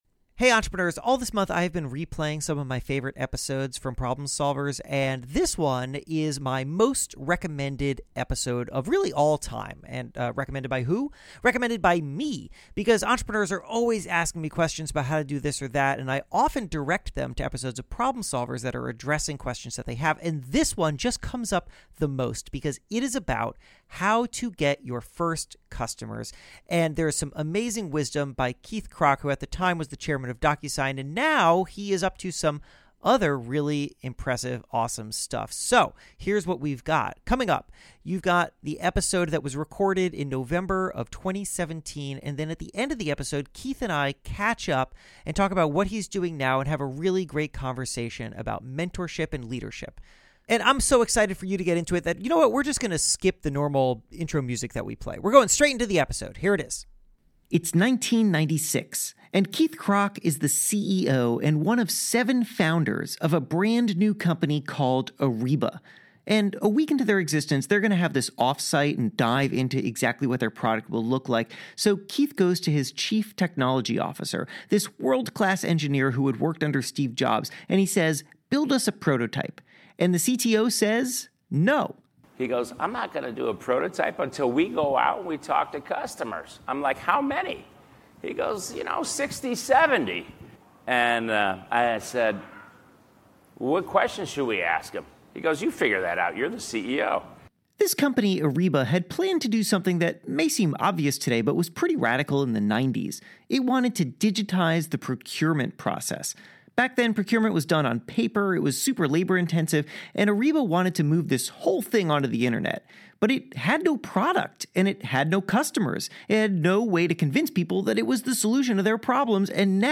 But Keith Krach has developed a tried-and-true strategy—starting during his days at Ariba and extending into his current time as chairman of Docusign. In this special live episode, taped at Entrepreneur Live in Los Angeles, Keith explains how to turn a company’s first customers into valuable ambassadors.